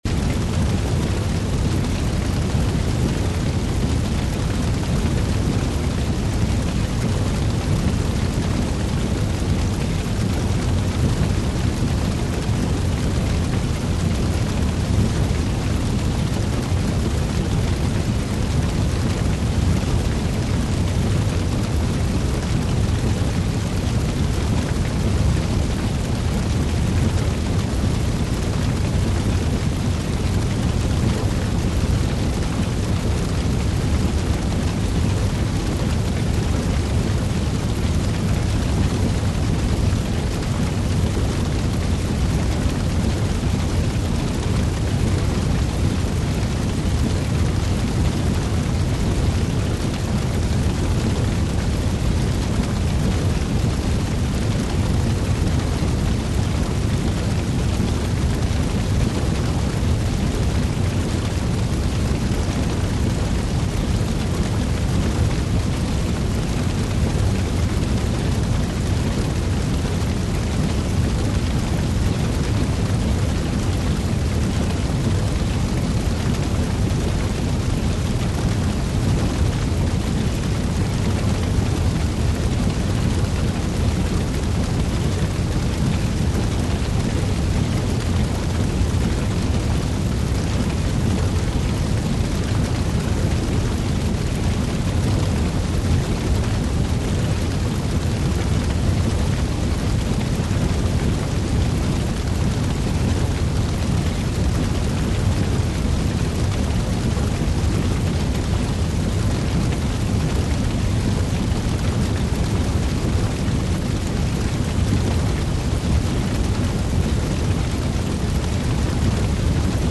пожар шумный резкий хрустящий